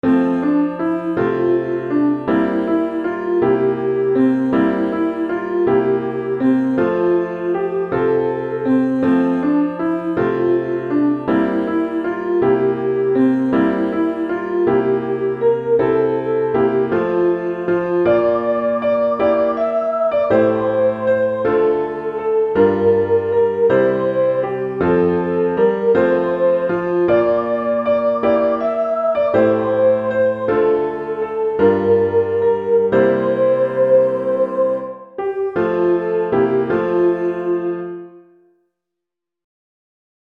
Vocal Solo